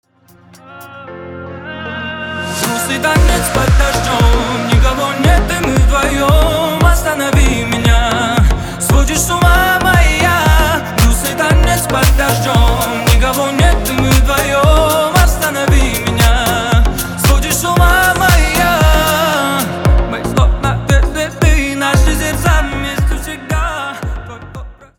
• Качество: 320, Stereo
поп
ритмичные
мужской вокал